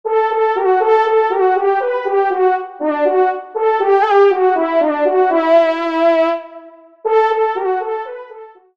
FANFARE
Merignac_Lot-Garonne-TDV_EXT.mp3